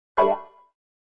На этой странице собраны классические звуки Windows Vista — системные уведомления, мелодии запуска и завершения работы, а также другие аудиоэлементы ОС.
Chord progression variations